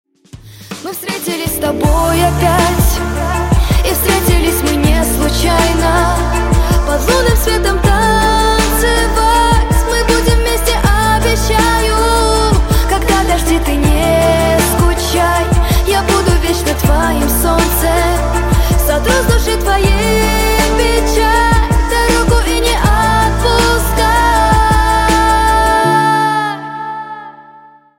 • Качество: 128, Stereo
поп
женский вокал